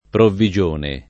[ provvi J1 ne ]